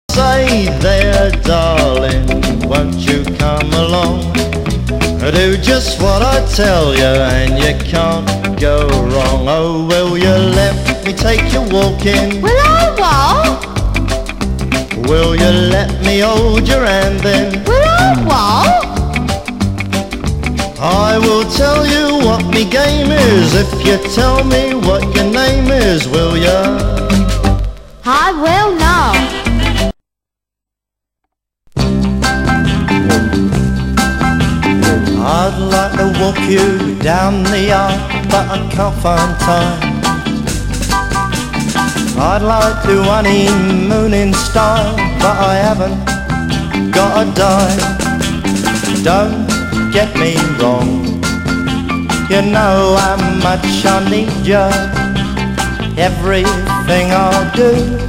B面はR&Bっぽい重厚なベースラインがカッコいい作風のティーンポップ。
(税込￥1650)   POP